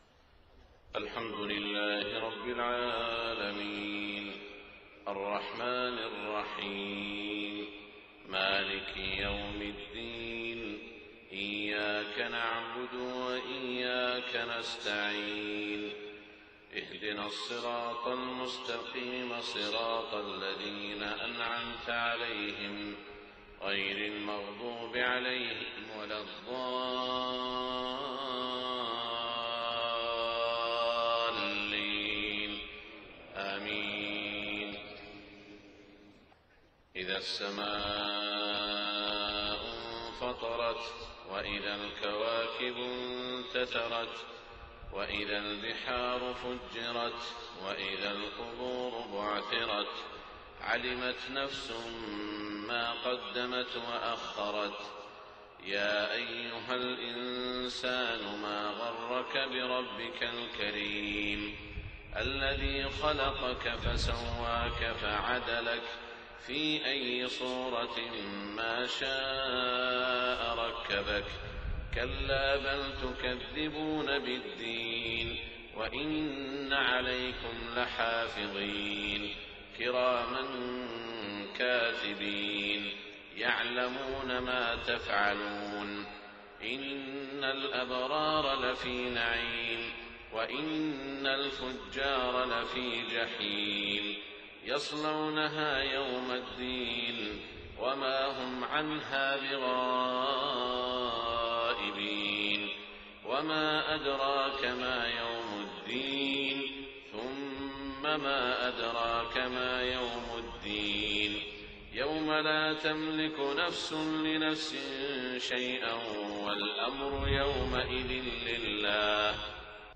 صلاة الفجر 7 محرم 1429هـ سورتي الانفطار و الأعلى > 1429 🕋 > الفروض - تلاوات الحرمين